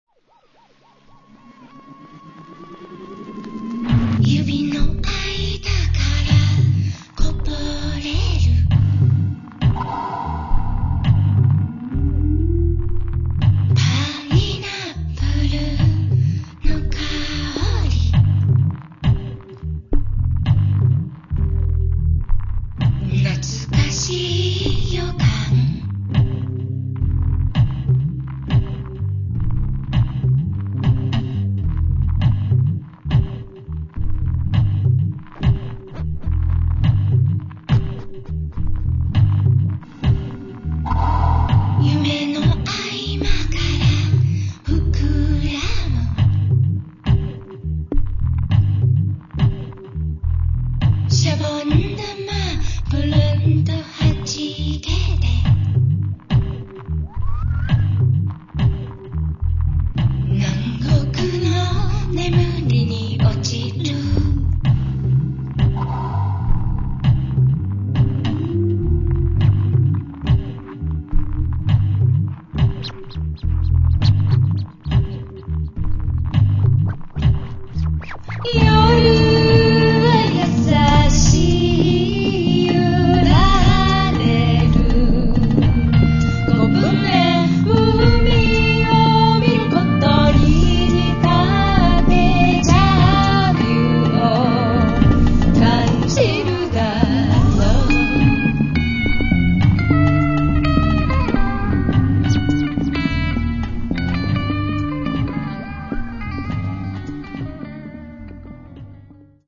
vocals, sampler, rhythm box, synthesizer
turntables